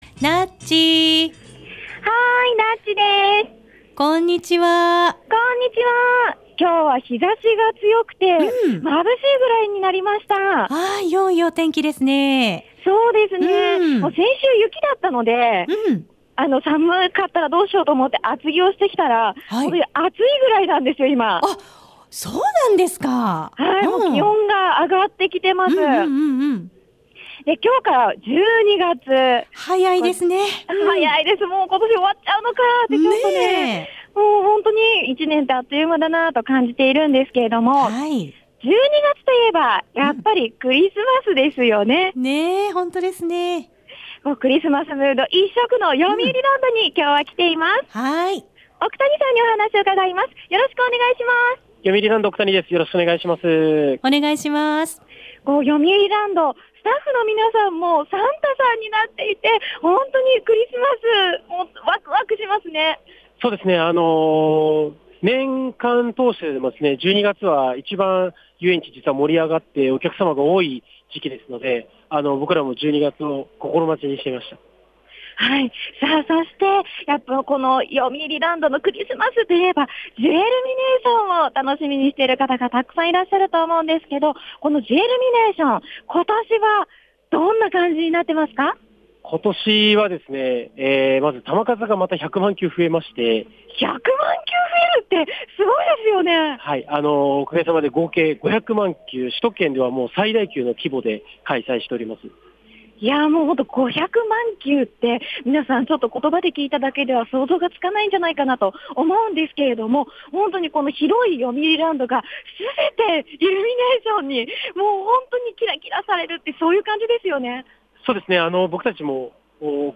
午後のカフェテラス 街角レポート
今日はクリスマスムード一色のよみうりランドに行ってきました☆